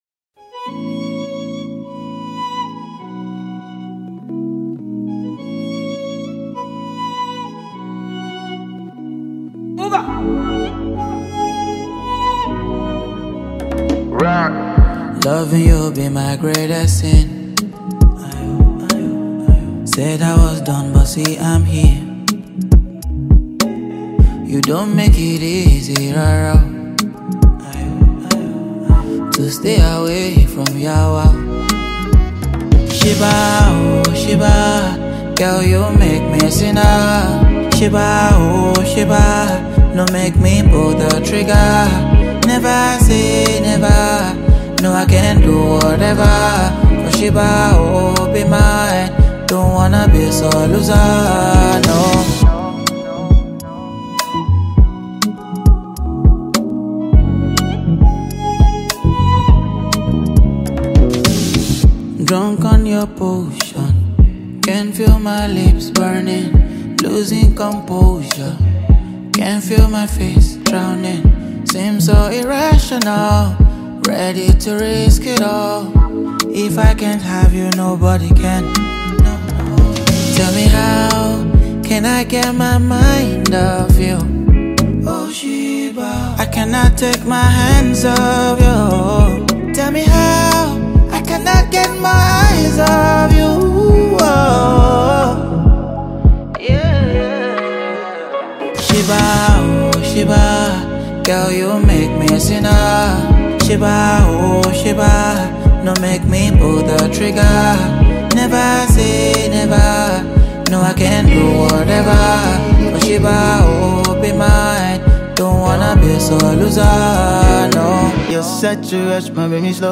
classic mid-tempo song